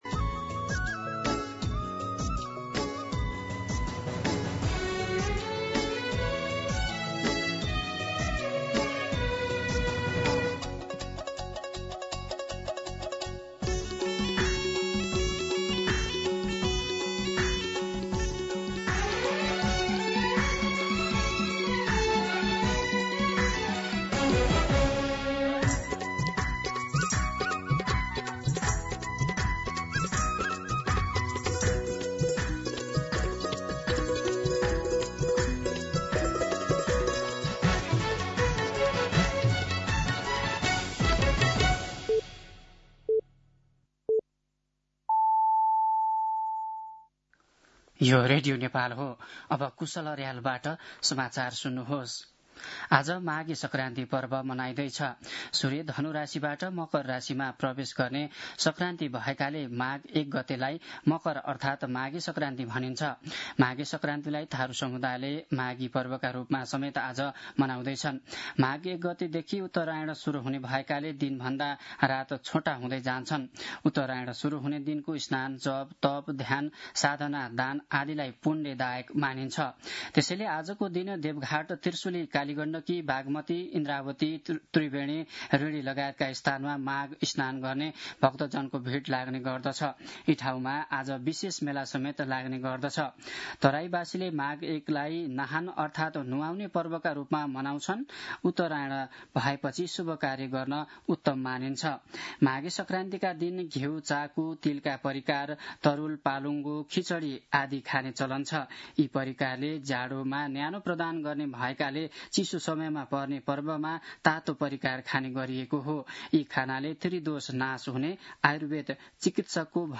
मध्यान्ह १२ बजेको नेपाली समाचार : १ माघ , २०८२
12-pm-Nepali-News-4.mp3